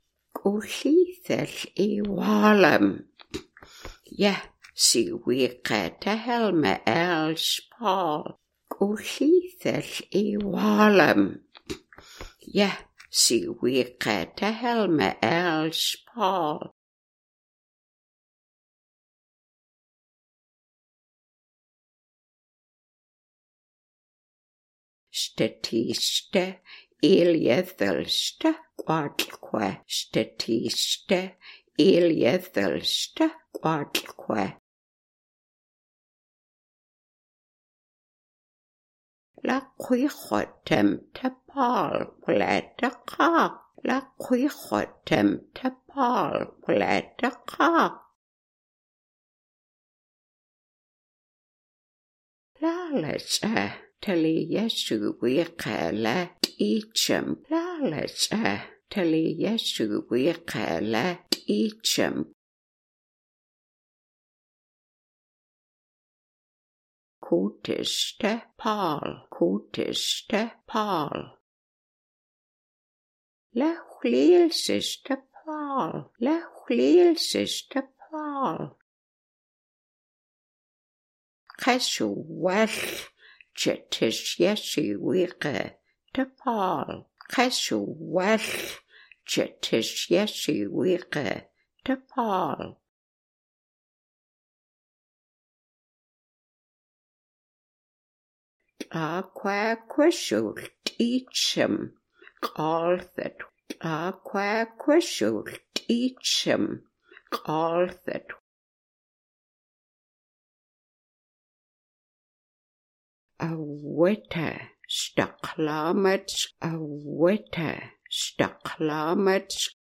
Listen to the elder